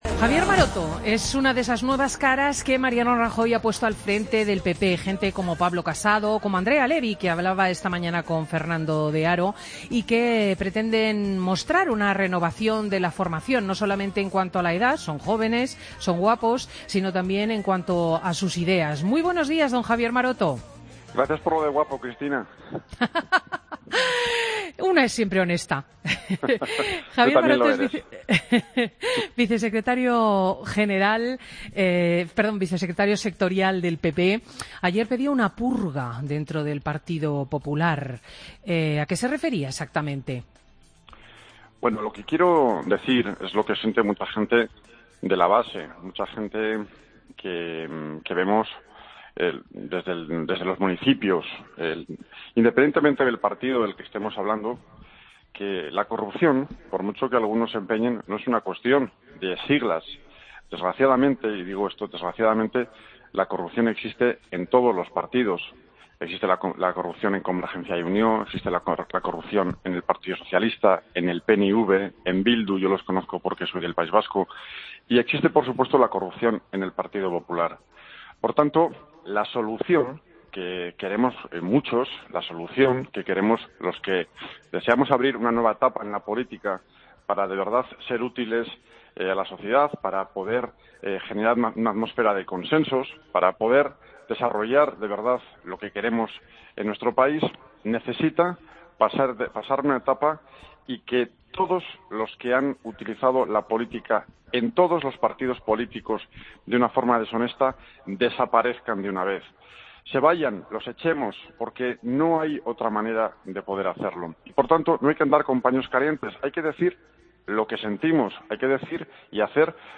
AUDIO: Escucha la entrevista a Javier Maroto, Vicesecretario Sectorial del PP, en Fin de Semana de Cope.